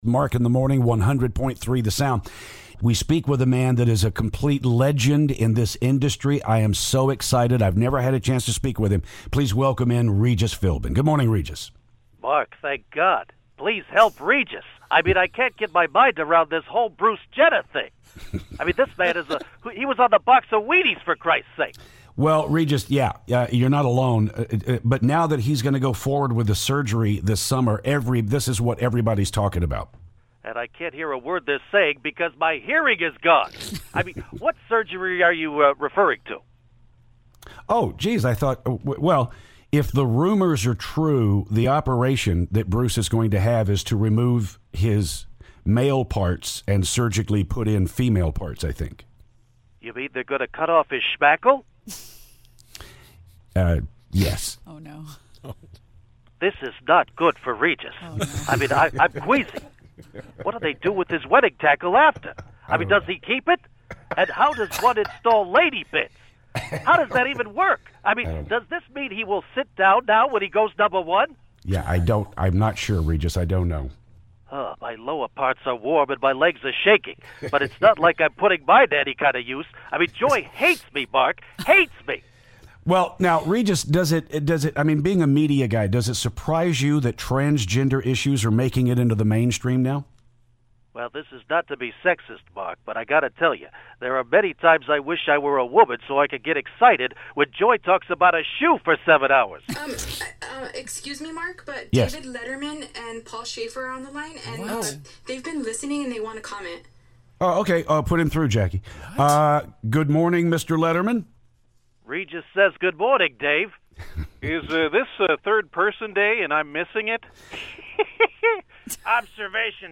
Regis calls the show with David Letterman and Paul Shaffer!